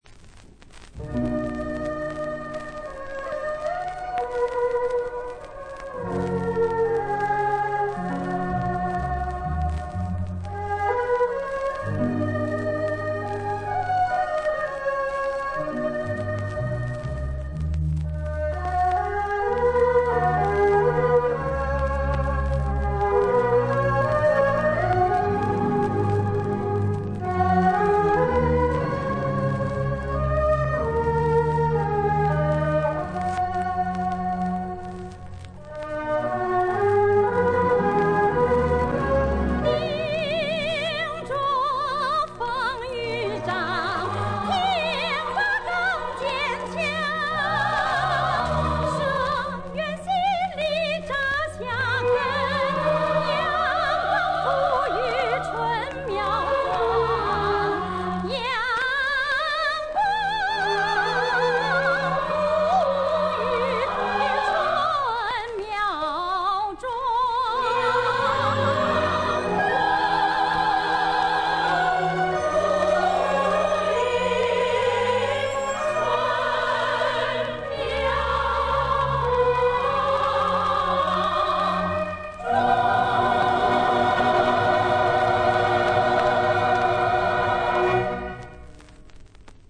插曲
（历史录音）